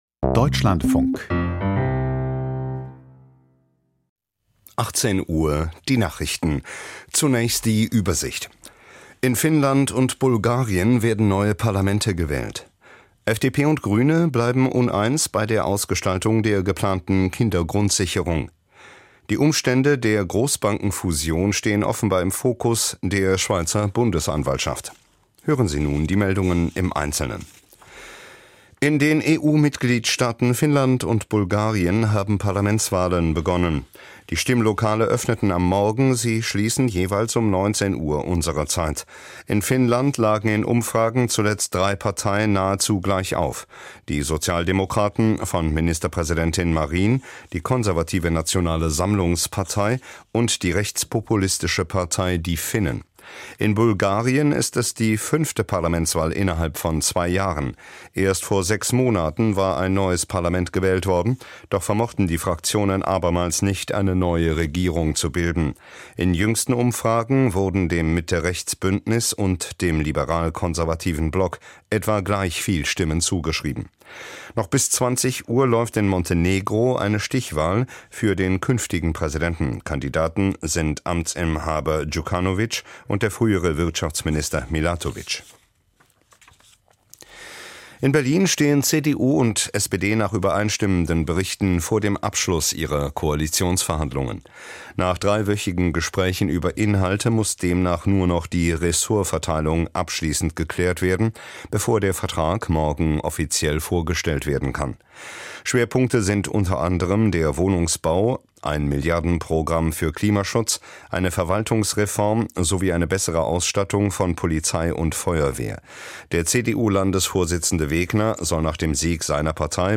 Nachrichten vom 02.04.2023, 18:00 Uhr